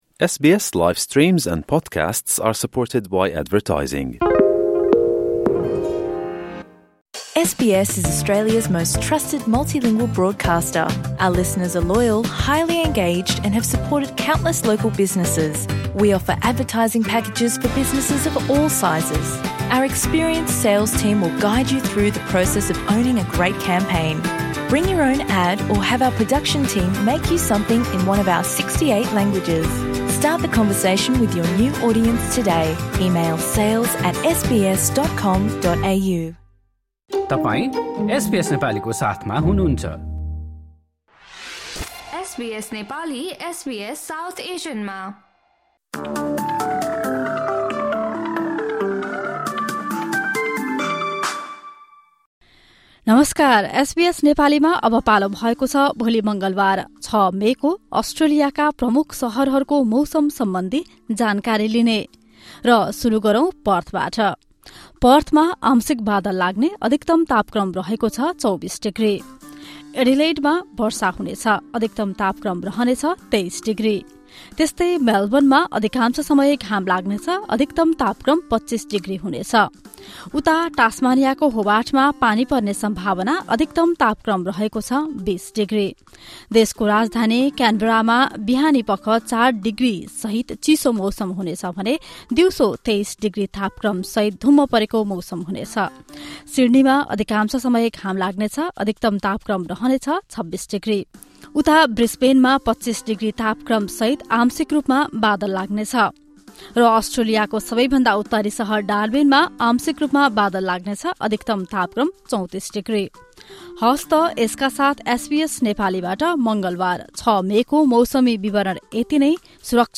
Australian weather update in the Nepali language.